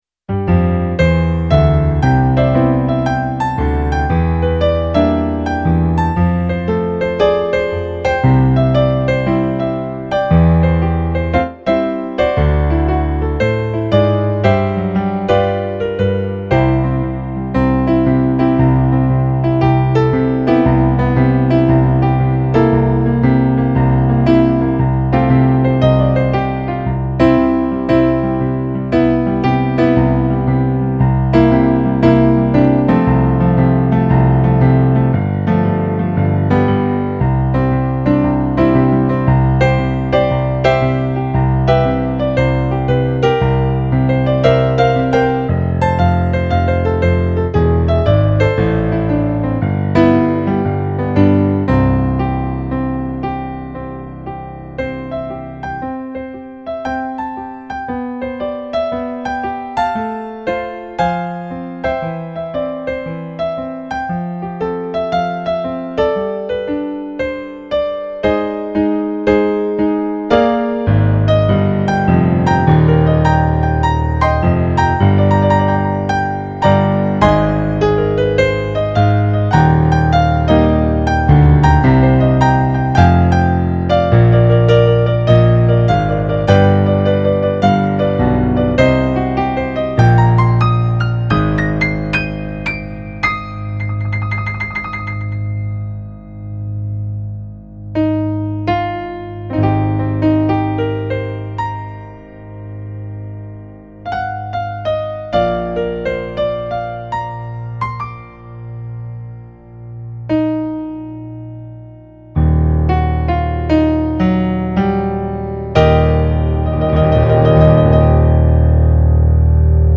eight piano solo arrangements.  31 pages.
swing remix